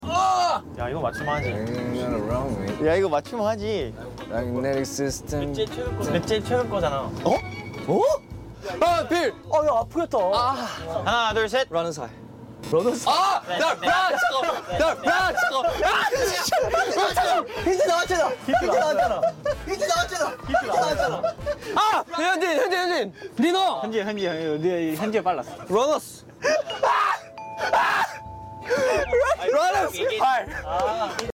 him whispering fuck under his sound effects free download